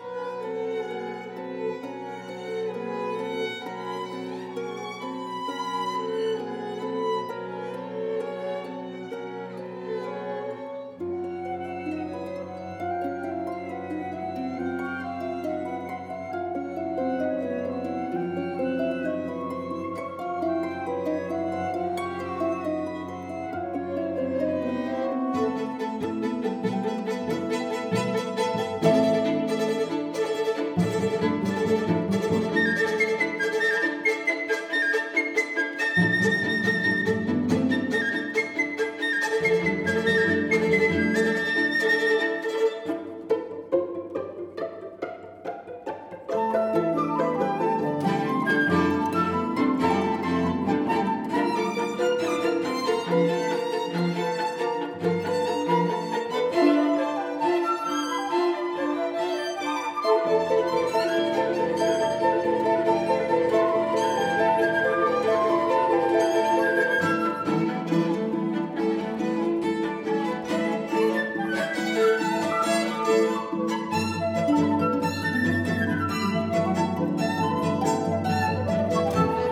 五重奏、室內樂作品